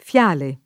fiale [ f L# le ] (raro fiare [ f L# re ] o fiaro [ f L# ro ])